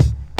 Kick (74).wav